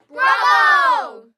Звуки аплодисментов
Дети кричат браво